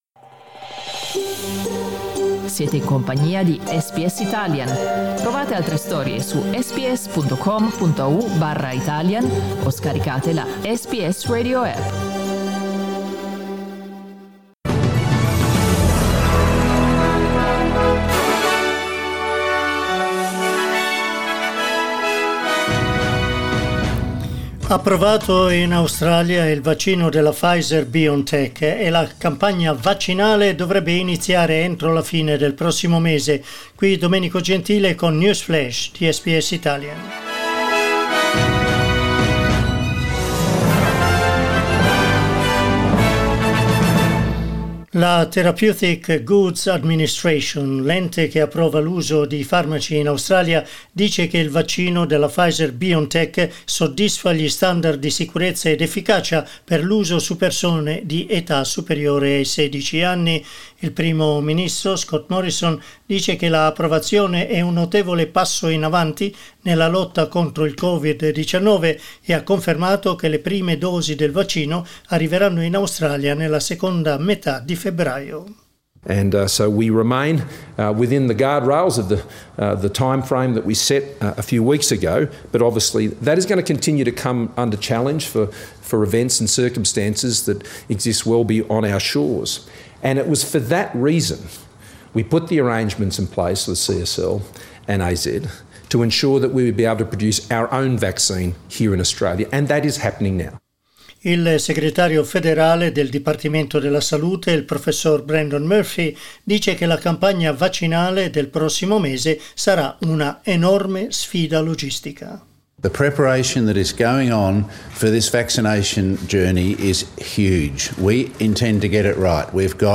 News Flash Monday 25 January 2021
Our news update in Italian.